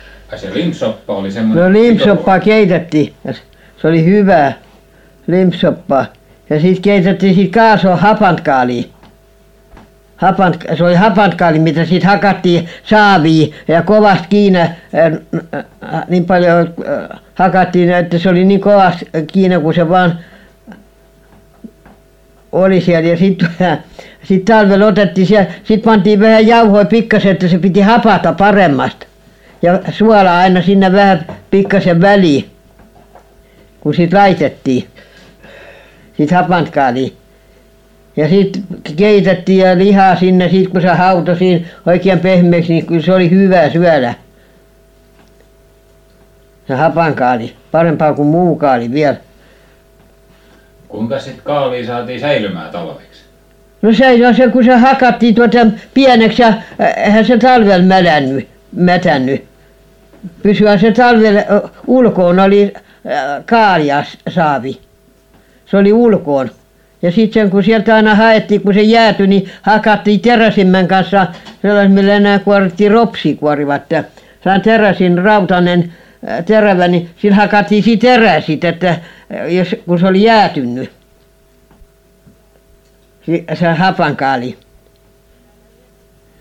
Inspelningarna innehåller huvudsakligen fritt tal av så kallade informanter, och berättelser om ämnen som de känner till, minnen och erfarenheter.
Inspelningarna gjordes från och med slutet av 1950-talet under intervjuresor med arkivets personal och insamlare som fått finansiering.